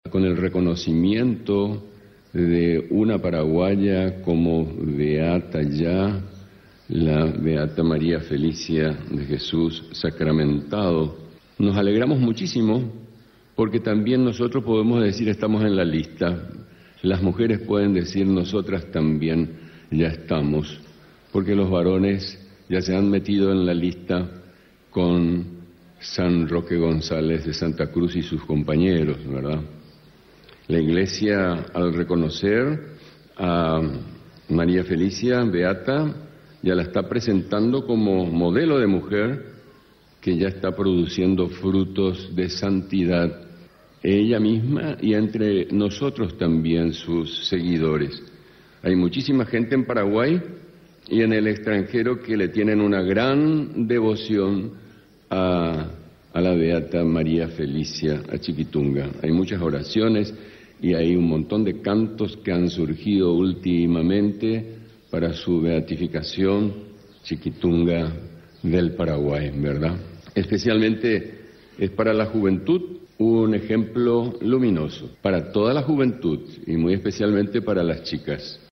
La Beata paraguaya Chiquitunga, es un modelo de mujer que produce frutos de santidad, resaltó el obispo emérito de Caacupé, monseñor Claudio Giménez, durante el último día del novenario a la Virgen de Caacupé.